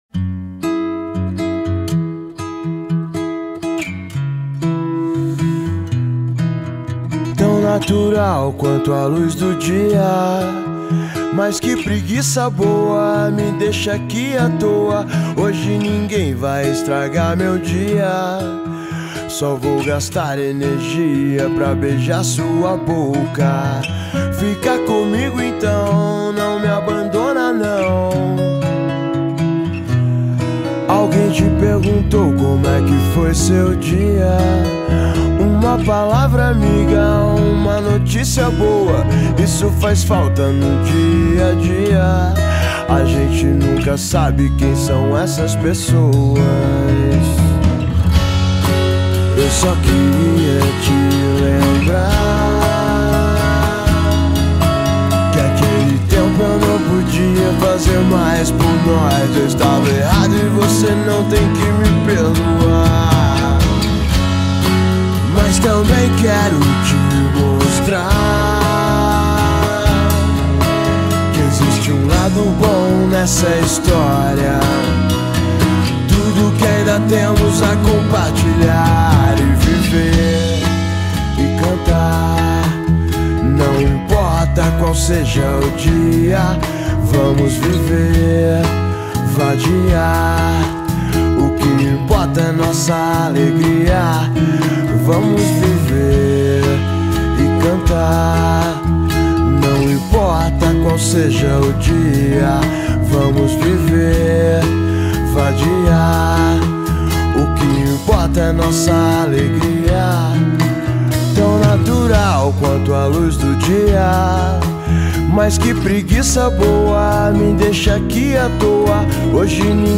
2025-02-23 01:11:53 Gênero: MPB Views